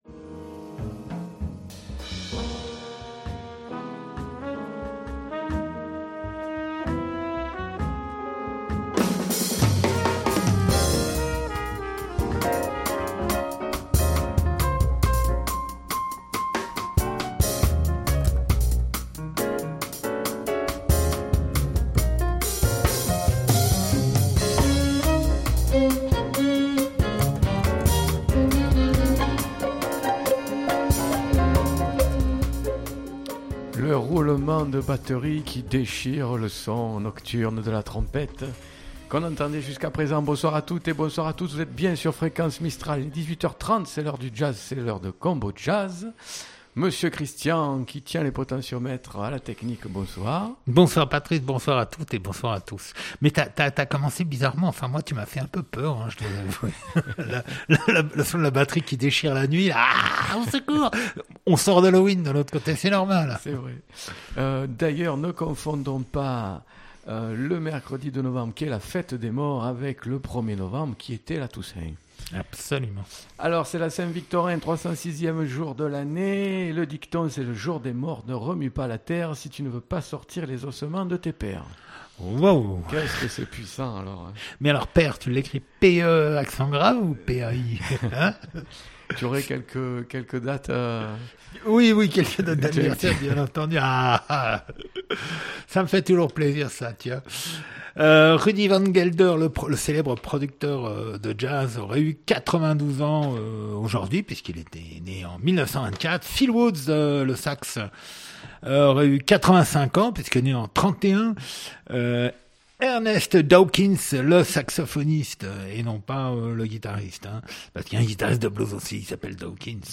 Combojazz du 2 Novembre 2016 Mercredi 2 Novembre 2016 Du jazz rien que du jazz 1 mercredi sur 2 de 18h30 à 20h00.